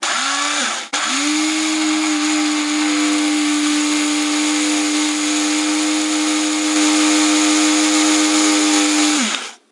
咖啡研磨机
描述：用sm57记录的咖啡研磨机
标签： 咖啡机 研磨机
声道立体声